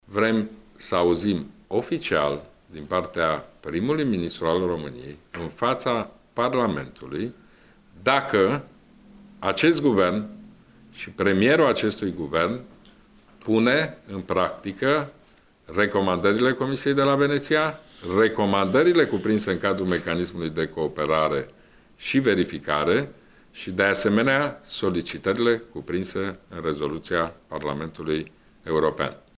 PNL va introduce o moţiune de cenzură în Parlament după data de 1 decembrie, a precizat la Iaşi, într-o conferinţă de presă, preşedintele partidului, Ludovic Orban.